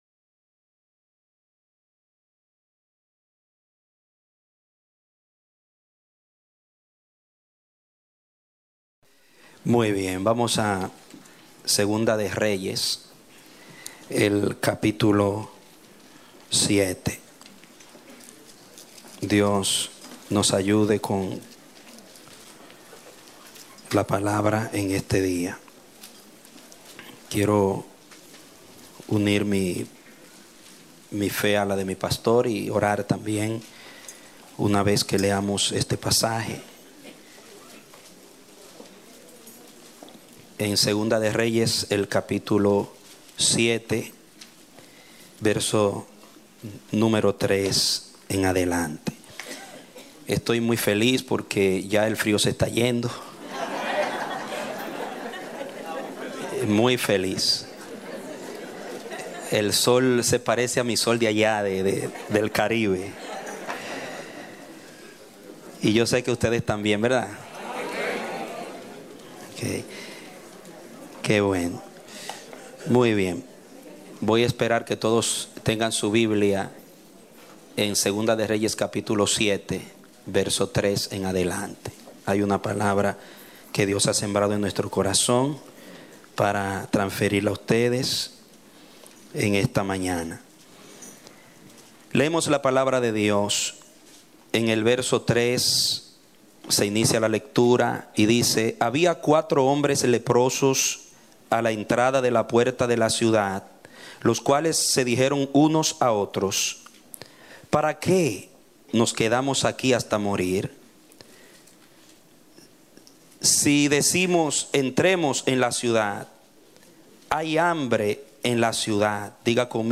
A mensaje from the serie "Hay Hambre en la Ciudad."